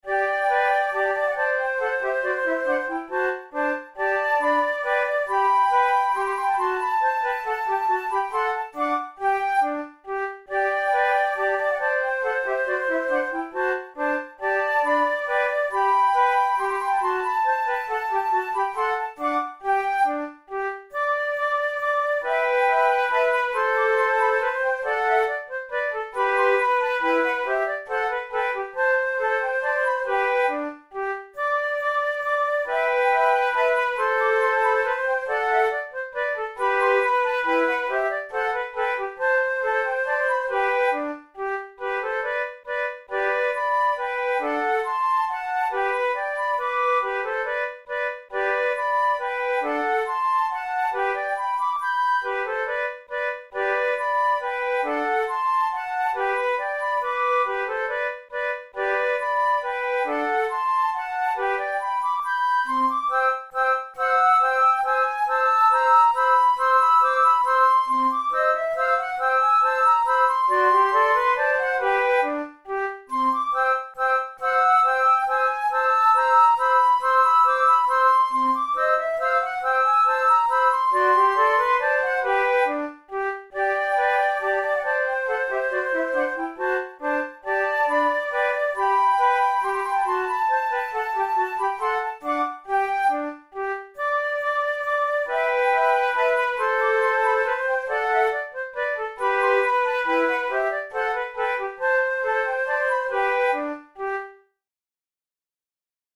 KeyG major
Time signature3/4
Tempo138 BPM
Classical, Dance tunes
arranged for flute trio